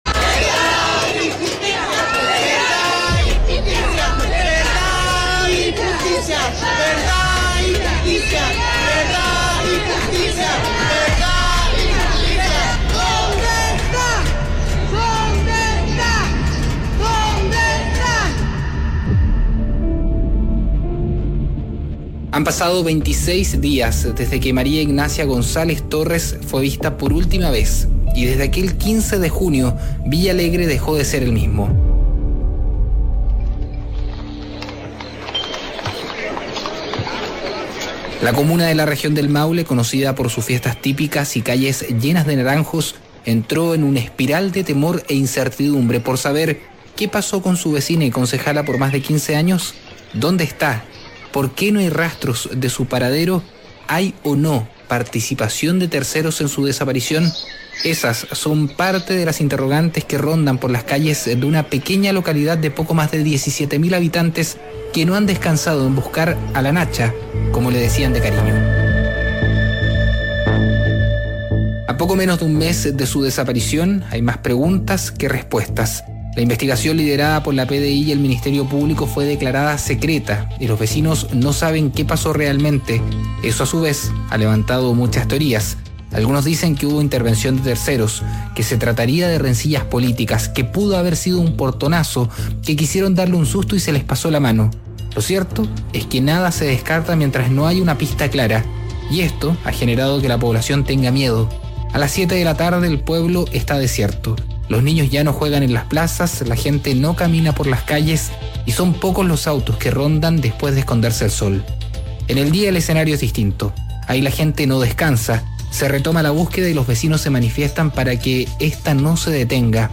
Radio ADN conversó en exclusiva con un testigo clave del caso, quien descartó que la autoridad comunal haya tenido intenciones de atentar contra su vida.